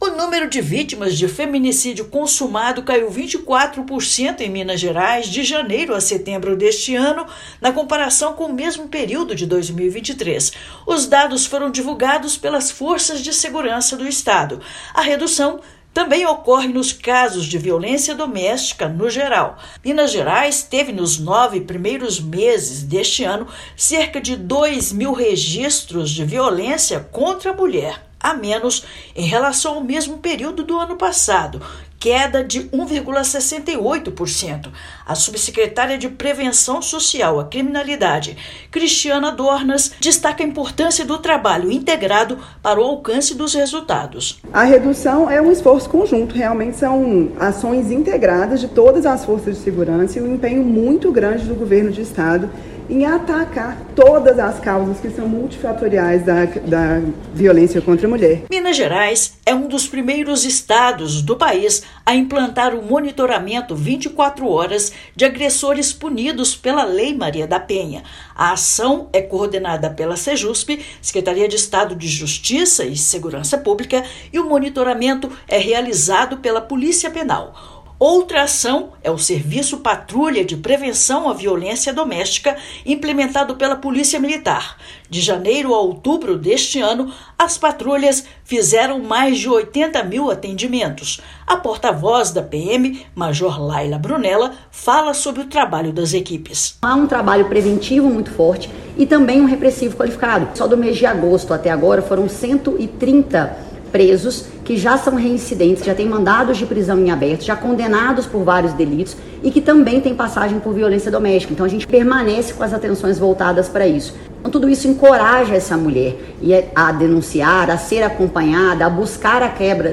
Estado também apresentou uma redução de 1,9 mil casos de violência contra a mulher. Ouça matéria de rádio.